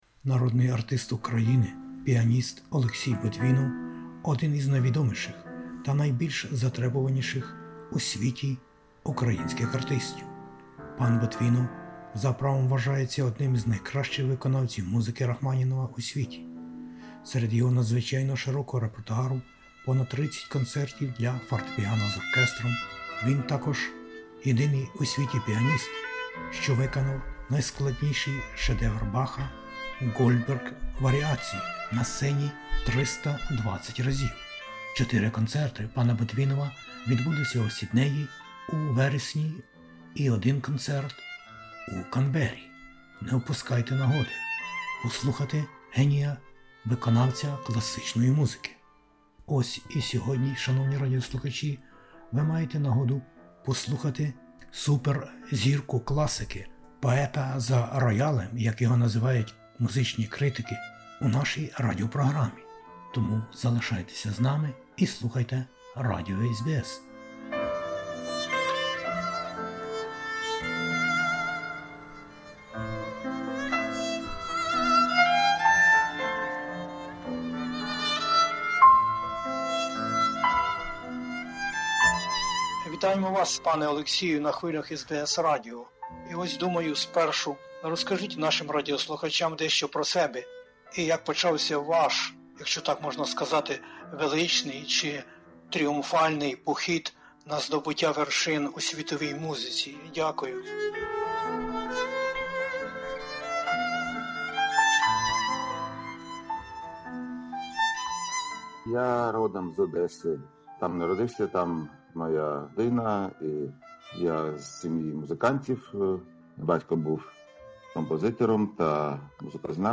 розмовляє із Народним артистом України Олексієм Ботвіновим із Одеси